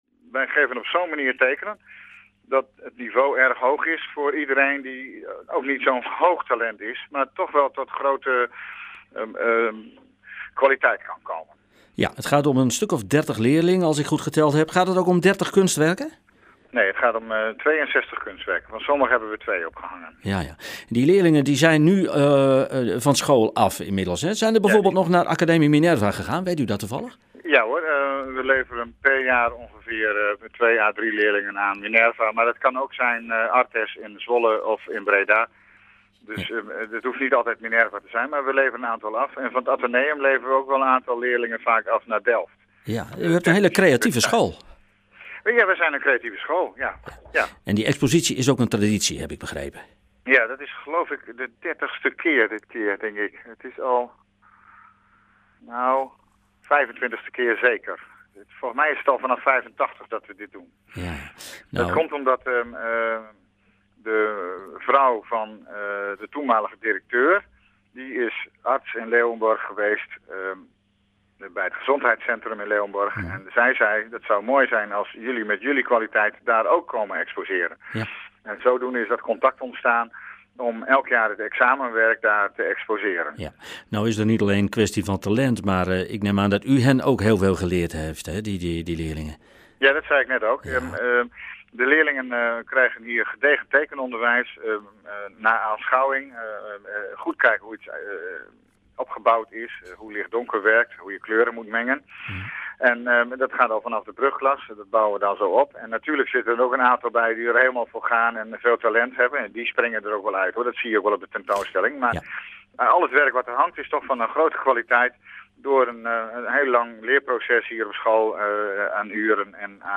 in gesprek met presentator